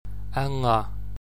I have pronounced them between two a’s sounds everytime, so it is clearer (sometimes sounds are not that clear at the beginning or at the end of a word).
My dialect is the Northwestern Donegal one.
broad ng.mp3